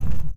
grenade_draw.wav